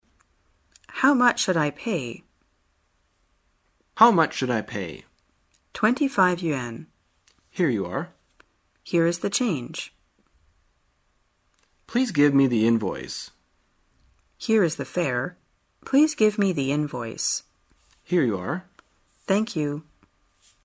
真人发音配字幕帮助英语爱好者们练习听力并进行口语跟读。